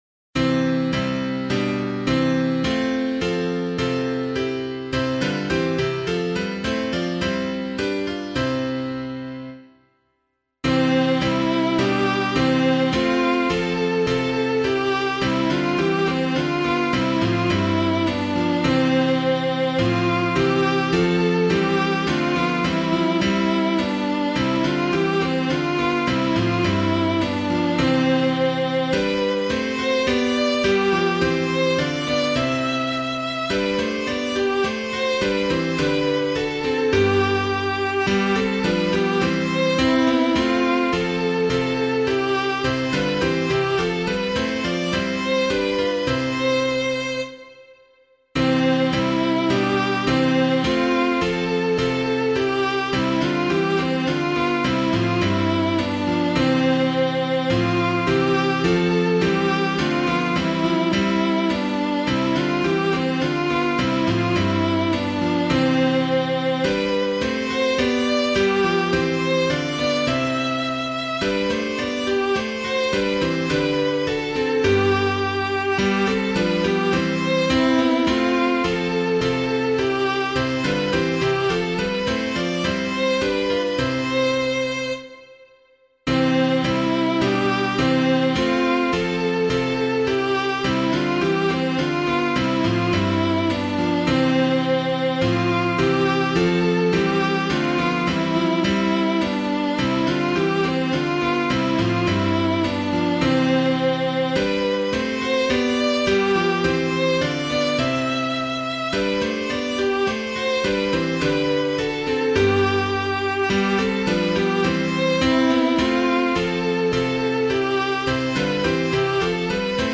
Christ the Lord Is Risen Again [Winkworth - EASTER HYMN] - piano.mp3